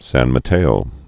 (săn mə-tāō)